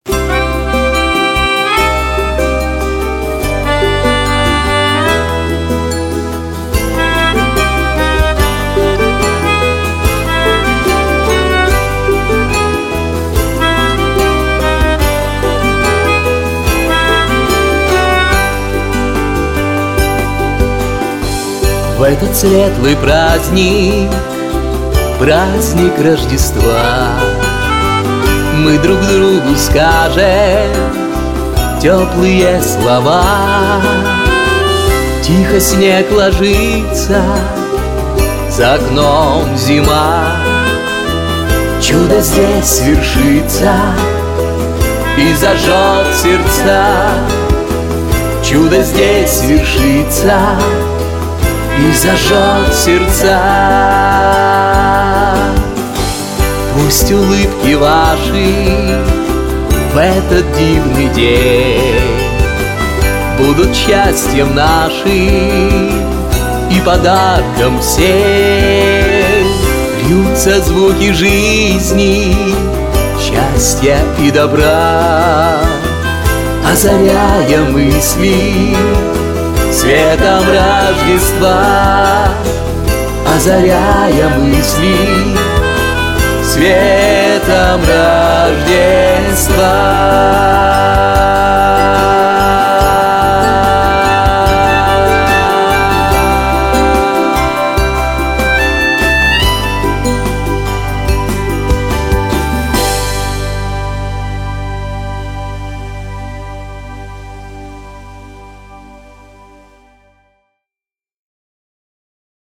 🎶 Детские песни / Песни на Рождество 🌟